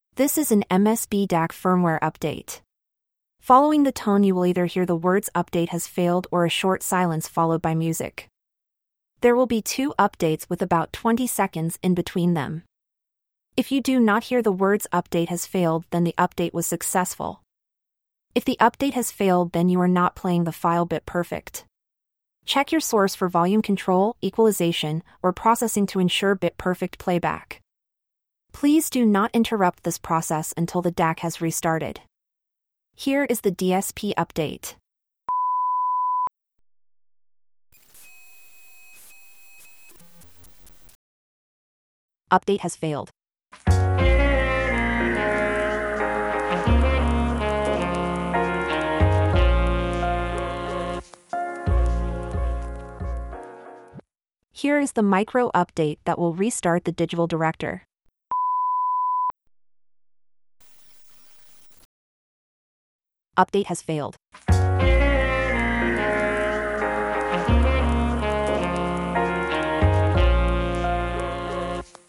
When you play the file you will hear instructions and two upgrade tones. Following each tone you will either hear silence for about 30 seconds (this varies) or you will hear the message ‘upgrade failed’.